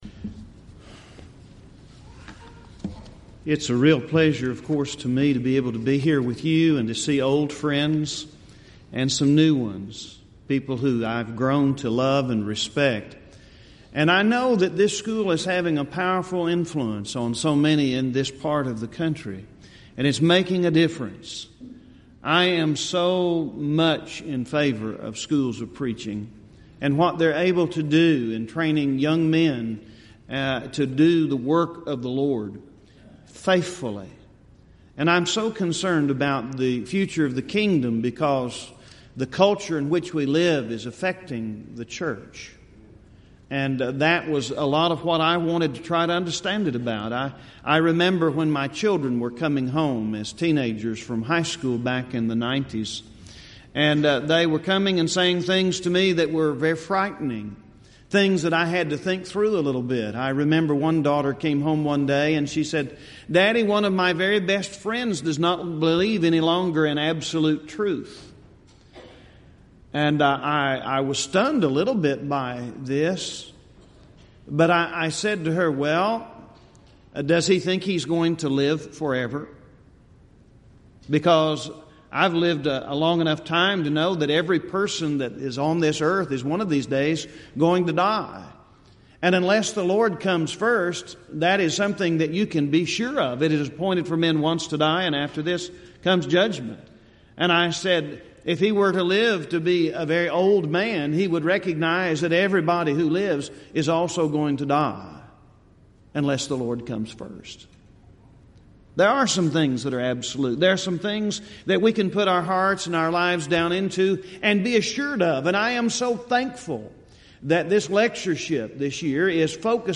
Series: Southwest Lectures Event: 30th Annual Southwest Bible Lectures